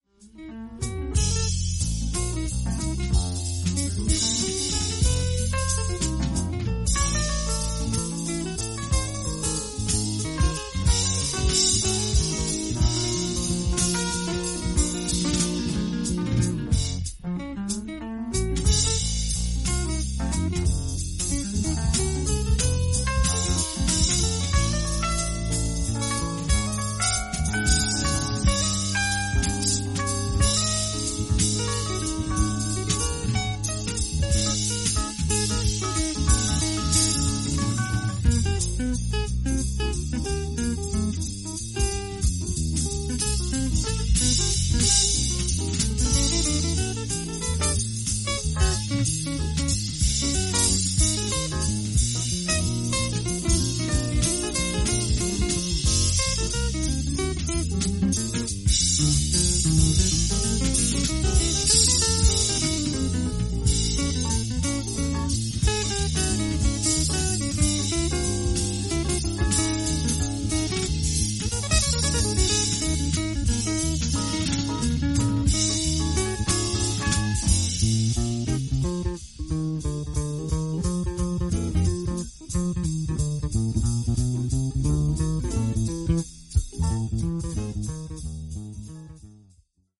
印象的なギターのフレーズからスタートするジャズワルツ